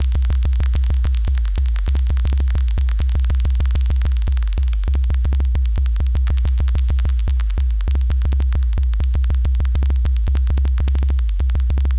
Glitch-loop2.wav